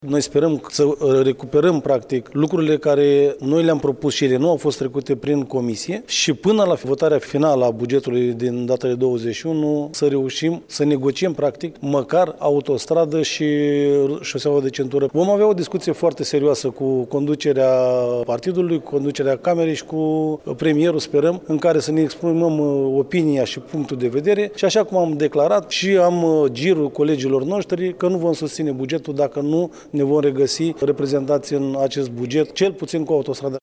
Afirmaţia a fost făcută, astăzi, de deputatul PSD de Iaşi, Vasile Câtea, care a participat la şedinţa Consiliului Local Iaşi.